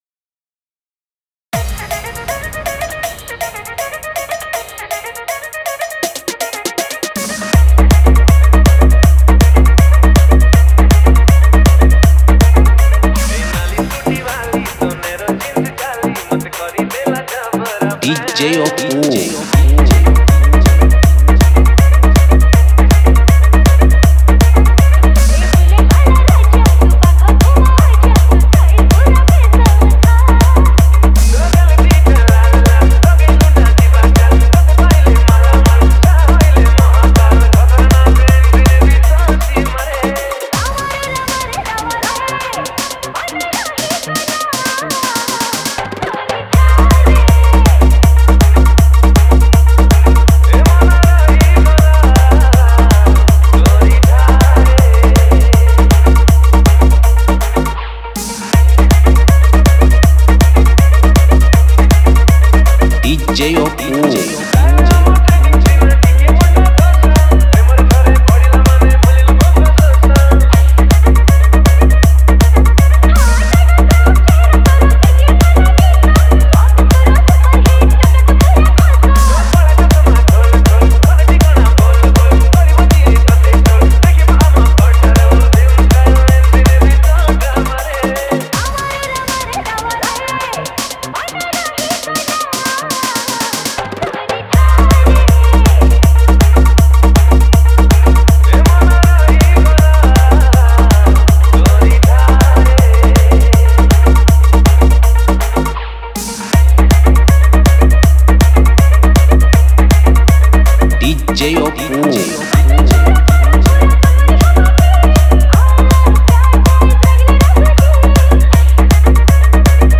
Tapori Power Dance Mix